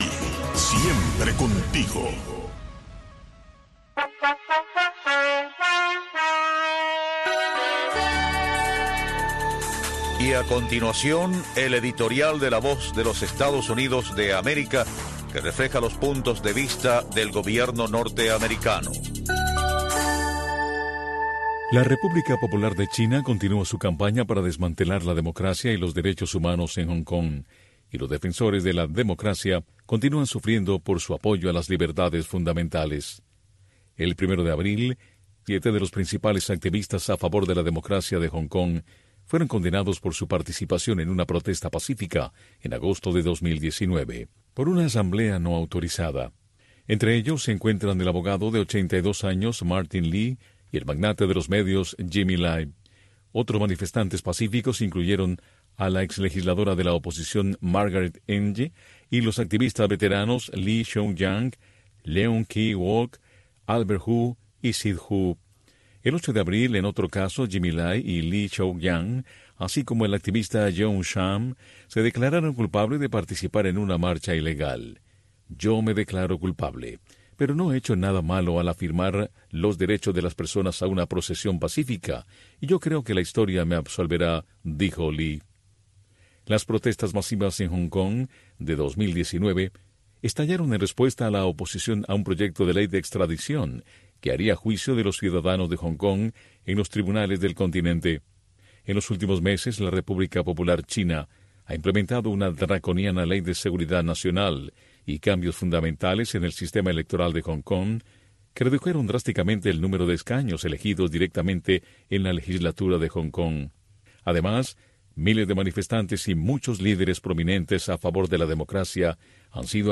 Radio Martí les ofrece una revista de entrevistas